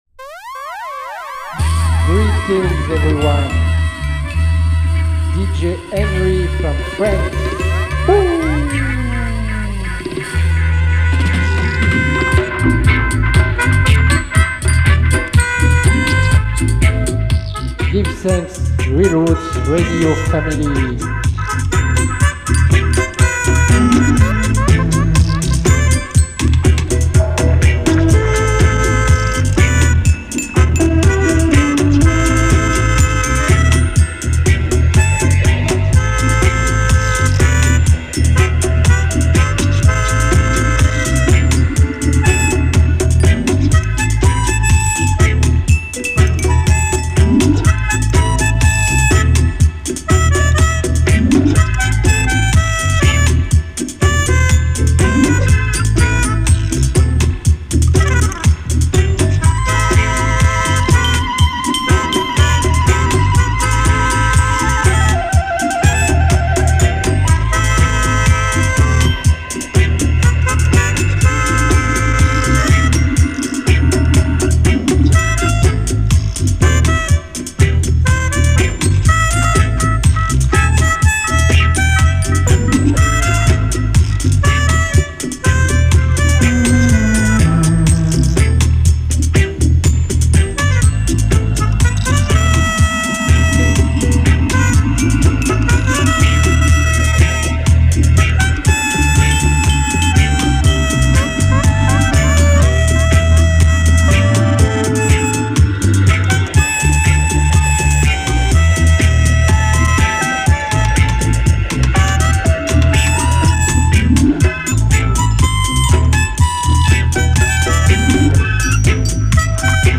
1 hour of positive roots reggae vibes